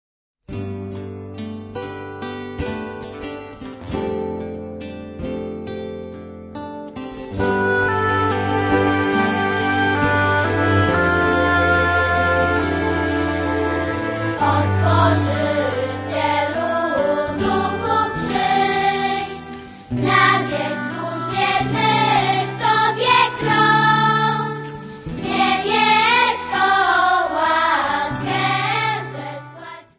Jolly contemporary religious Songs sung by Children.